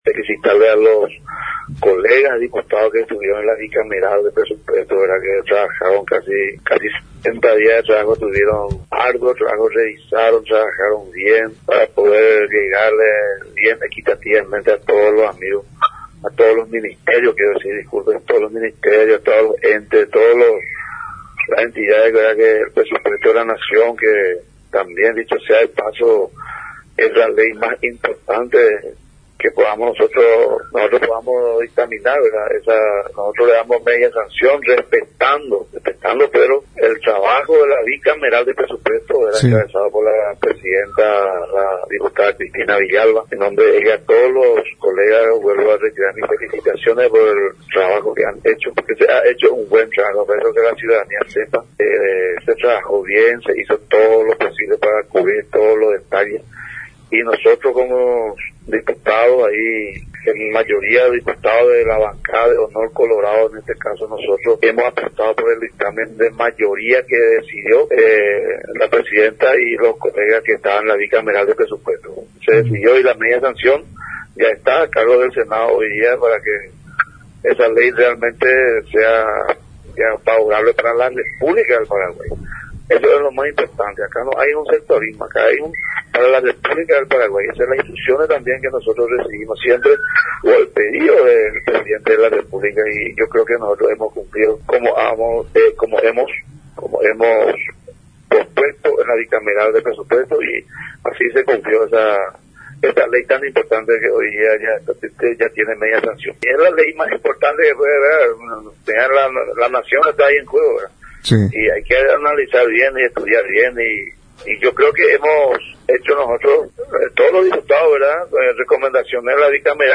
El Diputado sampedrano, Jorge Barresi, destacó la importancia del proyecto aprobado por sus colegas y de algunos recursos redireccionados que contribuirá a fortalecer el funcionamiento de los entes estatales. Además, se refirió al aumento de presupuesto para las gobernaciones.
EDITADO-1-JORGE-BARRESI-DIPUTADO.mp3